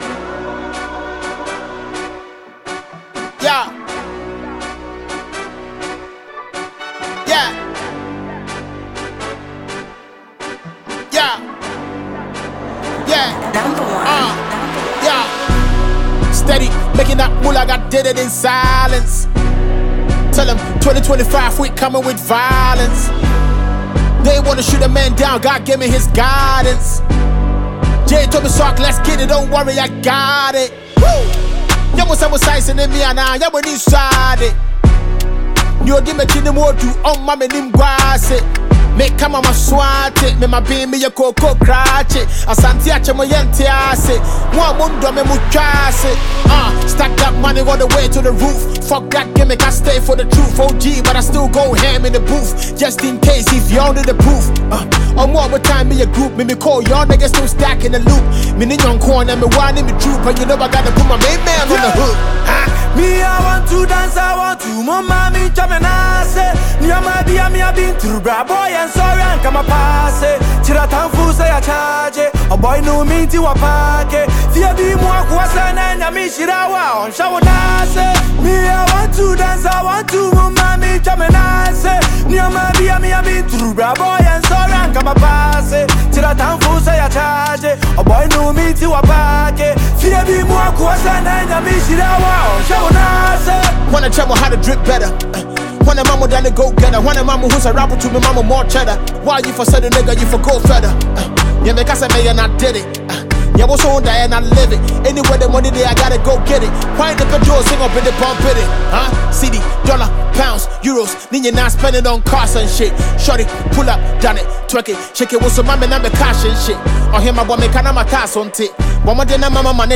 two award-winning rappers.